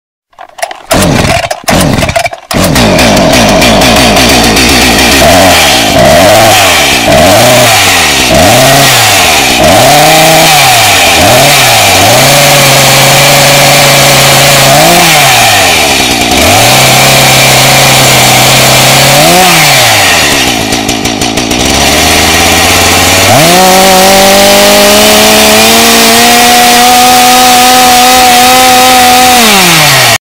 MHbwQUJX1Ge_chainsaw-earrape.mp3